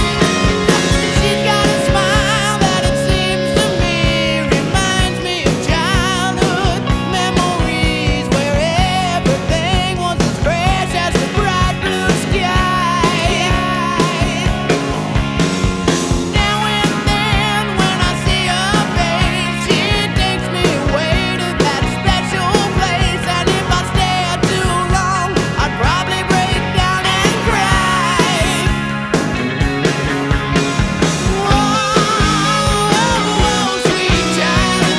Жанр: Рок / Пост-хардкор / Хард-рок
# Hard Rock